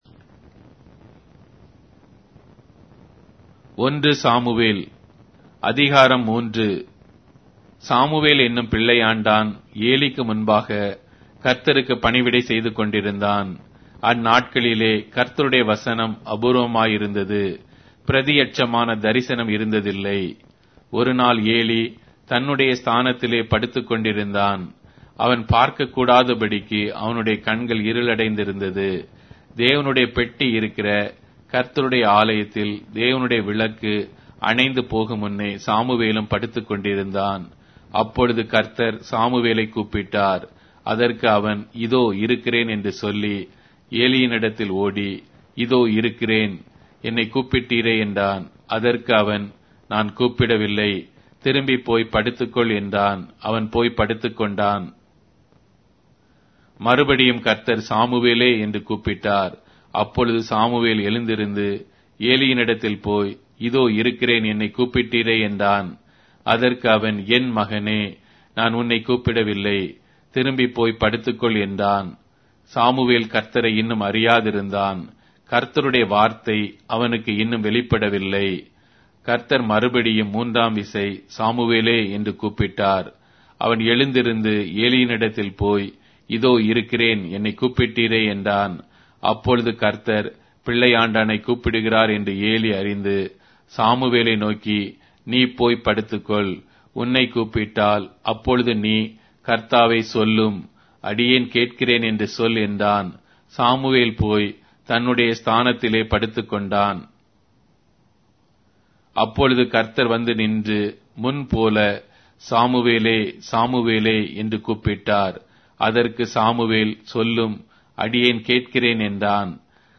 Tamil Audio Bible - 1-Samuel 17 in Irvgu bible version